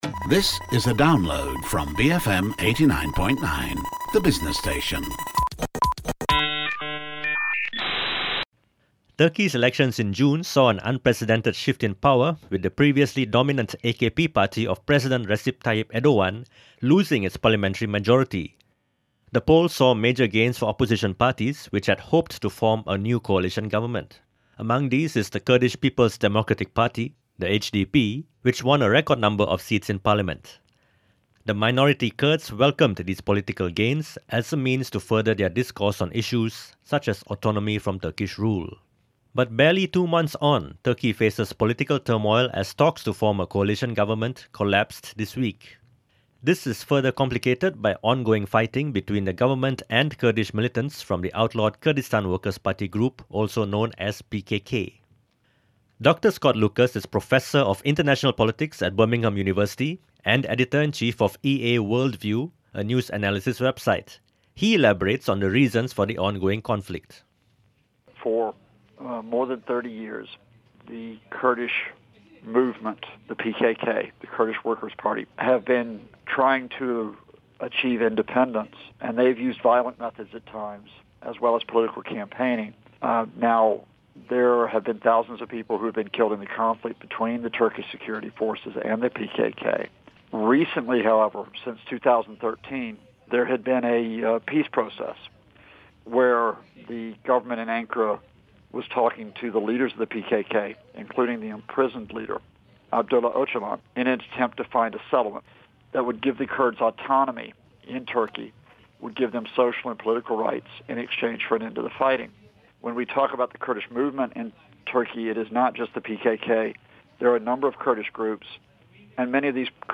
I spoke with Malaysia’s BFM Radio on Thursday about the challenges facing the Turkish Government, including the Kurdish issue and the Syrian crisis.